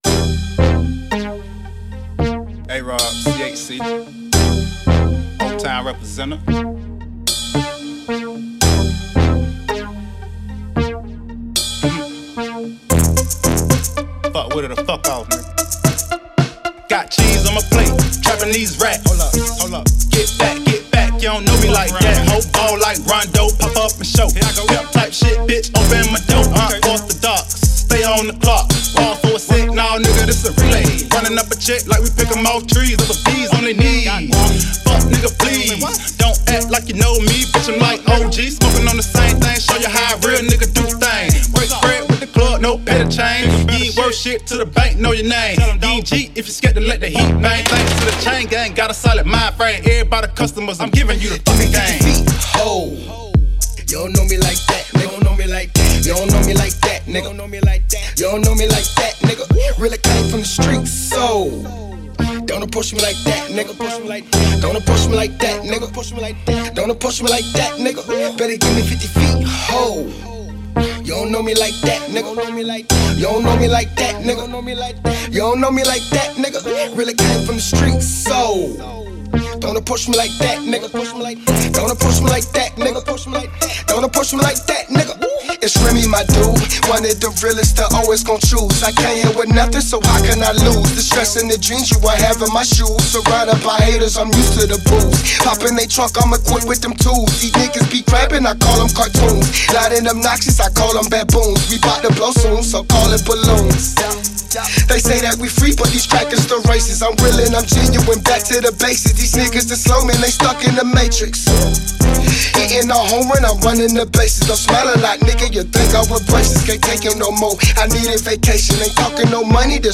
Hiphop
Description: Southern Hip Hop